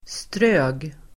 Ladda ner uttalet